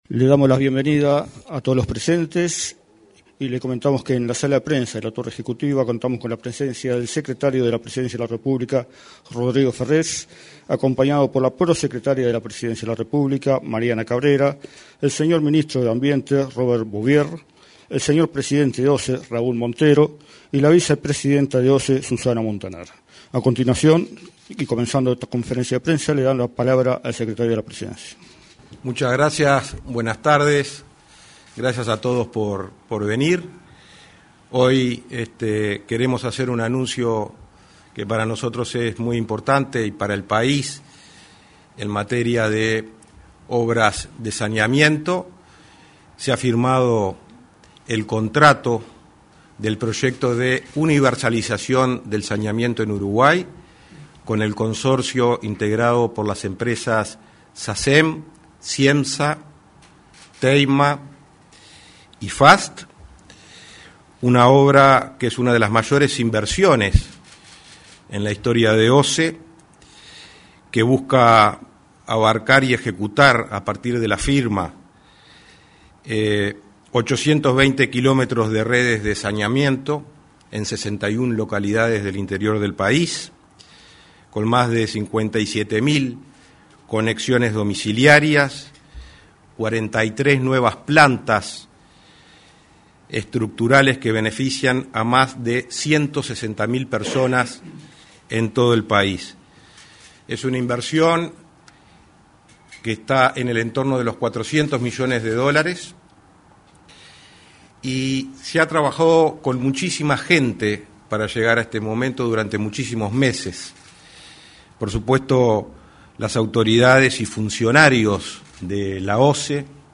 Conferencia de prensa sobre plan de universalización de saneamiento
Conferencia de prensa sobre plan de universalización de saneamiento 29/07/2024 Compartir Facebook X Copiar enlace WhatsApp LinkedIn Este 29 de julio, se realizó una conferencia de prensa en la Torre Ejecutiva, en la cual se expresaron el secretario de la Presidencia de la República, Rodrigo Ferrés; el ministro de Ambiente, Robert Bouvier, y el presidente del directorio de OSE, Raúl Montero, respecto a un plan de universalización del saneamiento.